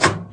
Sons et bruitages gratuits de cuisines
Ouvrir porte micro onde